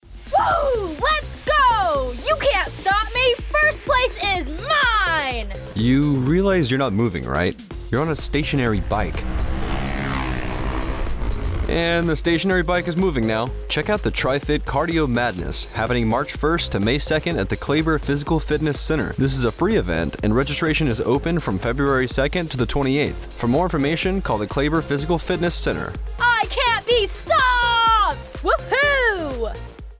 Radio Spot - Tri-Fit Cardio Madness AFN Kaiserslautern